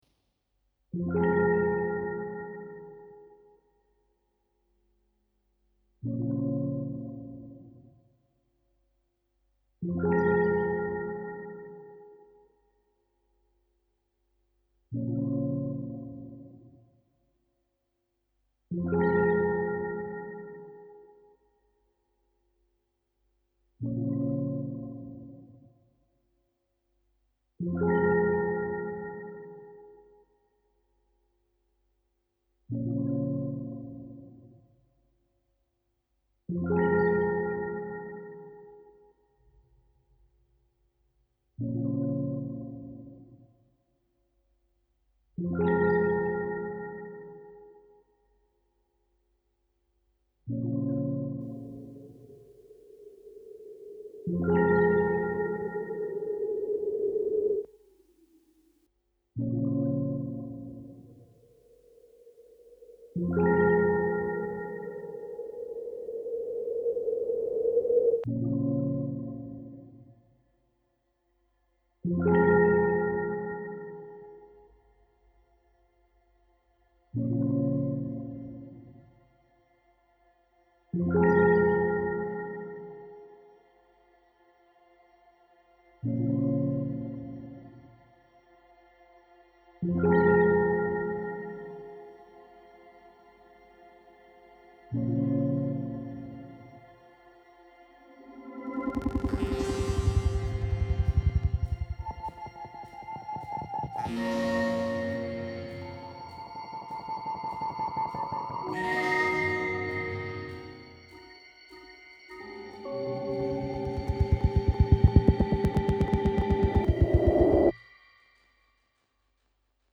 Slow reflective and sparse with a hint of magic.